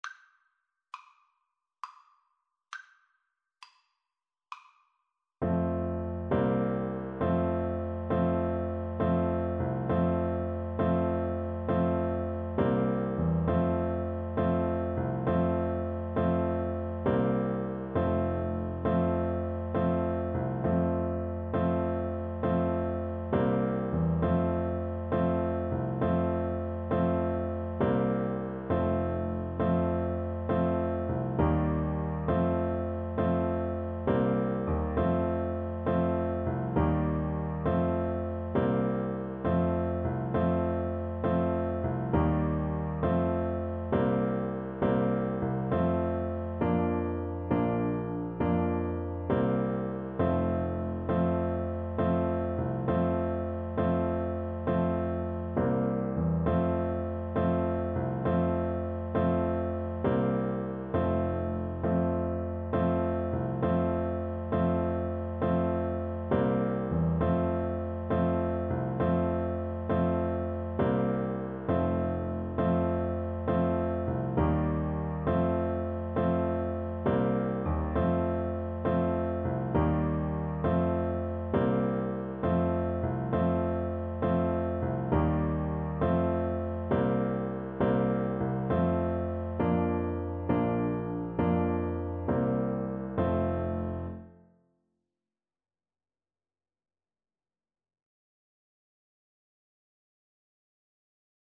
Traditional Music of unknown author.
9/8 (View more 9/8 Music)
F major (Sounding Pitch) (View more F major Music for Bassoon )